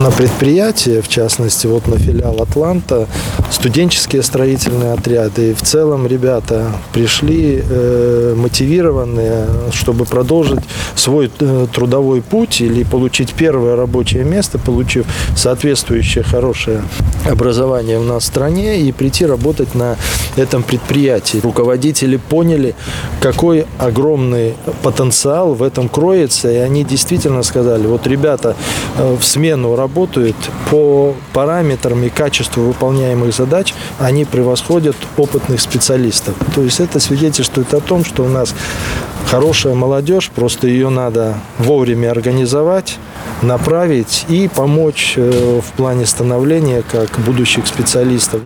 На Барановичском станкостроительном заводе «Атлант» работают студенческие отряды, проходят производственную практику молодые ребята из колледжей и вузов, и это уже серьезная мотивация, чтобы прийти сюда после окончания учебных заведений или службы в армии и начать трудиться, как это и делает большинство молодежи, — отметил в интервью журналистам вице-премьер республики Игорь Петришенко. И это уже элемент патриотизма по отношению к собственной стране, городу, тому предприятию, где получил первые трудовые навыки.